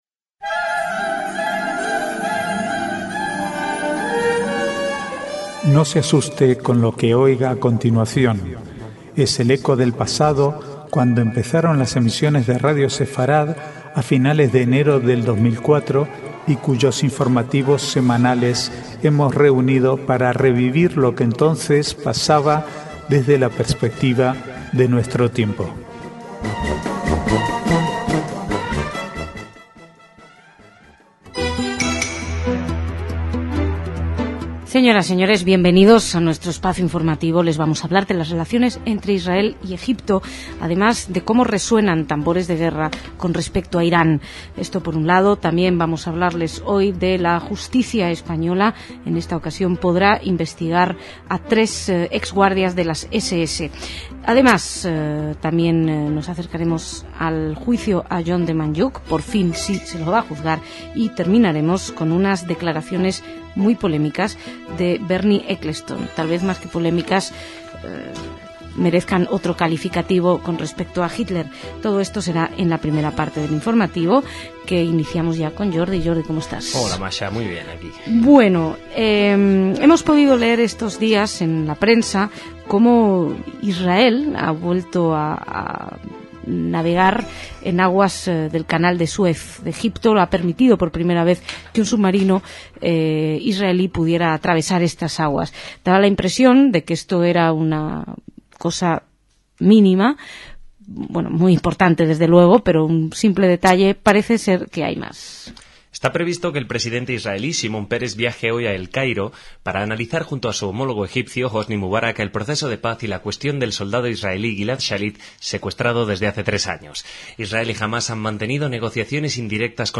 Archivo de noticias del 7 al 9/7/2009